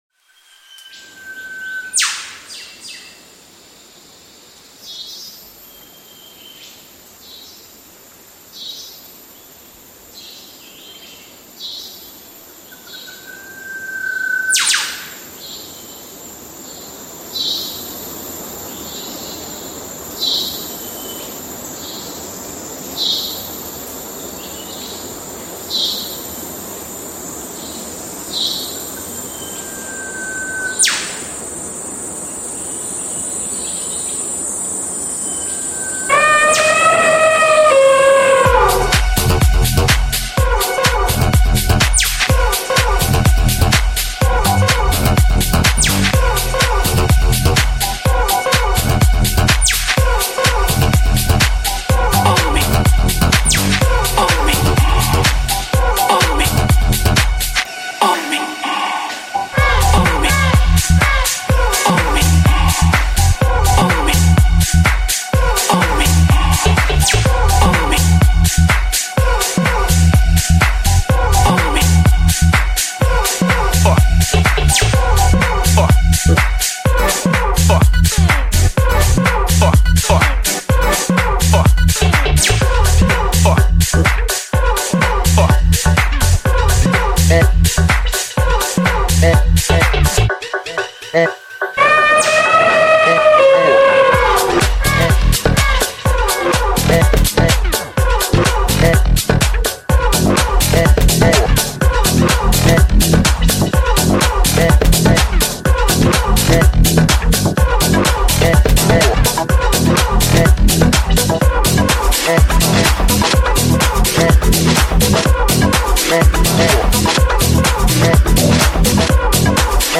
Genre - TECH HOUSE
BPM - 125